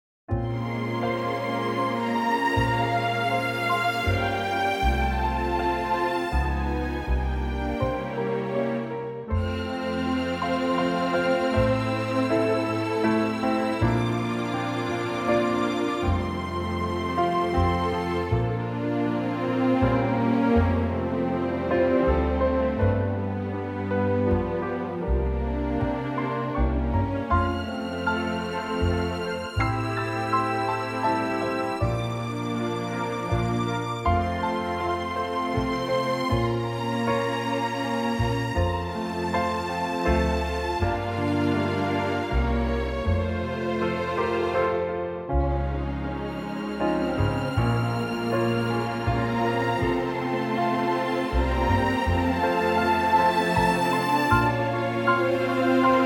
Unique Backing Tracks
key - F - vocal range - Bb to D
Lovely orchestral arrangement
in a much lower key.